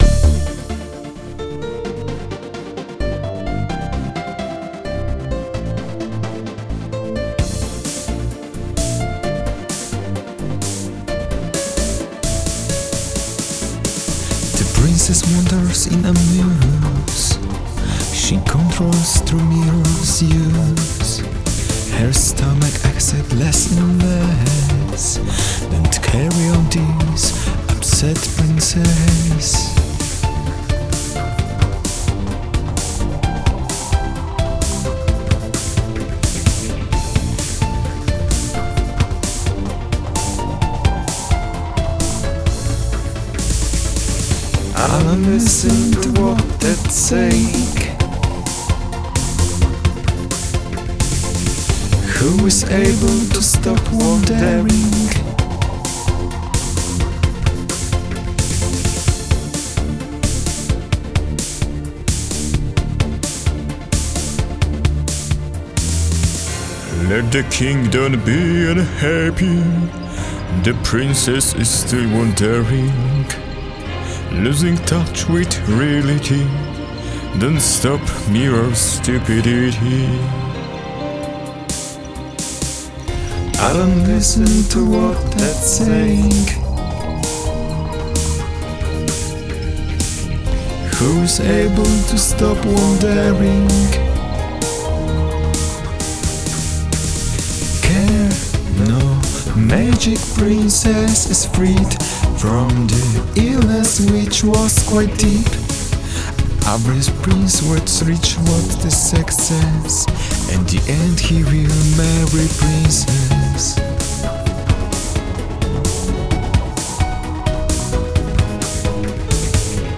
Mystery voice
Bad monster voice
Fable happy -end voice